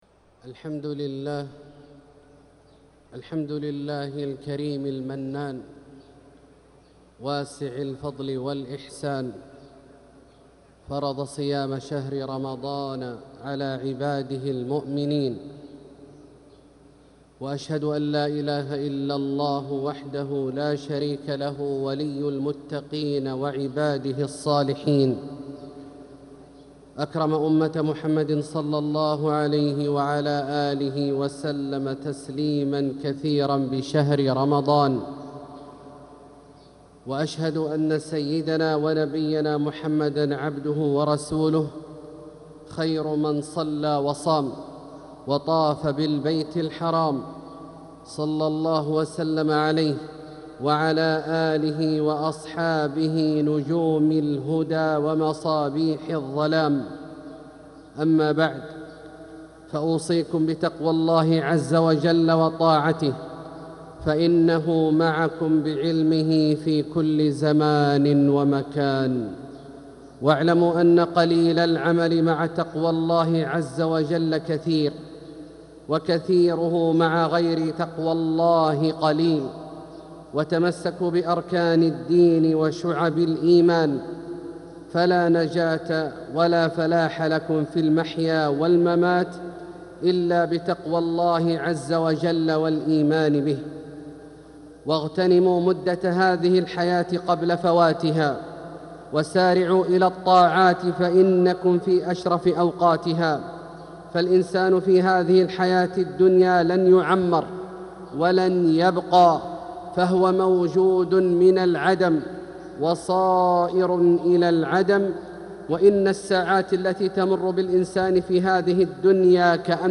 خطبة الجمعة 7-9-1446هـ | Khutbah Jumu’ah 7-3-2025 > خطب الحرم المكي عام 1446 🕋 > خطب الحرم المكي 🕋 > المزيد - تلاوات الحرمين